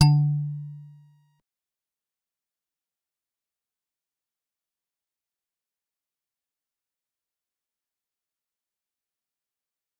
G_Musicbox-D3-pp.wav